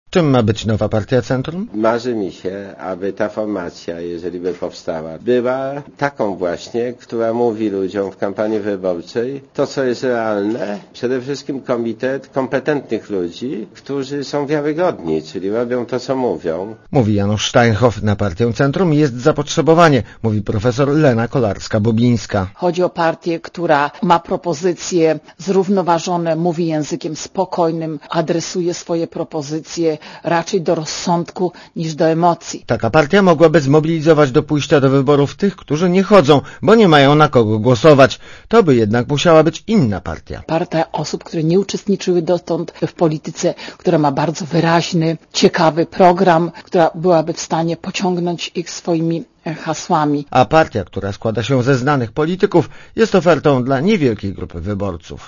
Środowiska Unii Wolności i zwolennicy Janusza Steinhoffa z partii Centrum ciągle rozmawiają. Źródło: PAP Relacja reportera Radia ZET Oceń jakość naszego artykułu: Twoja opinia pozwala nam tworzyć lepsze treści.